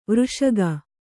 ♪ vřṣaga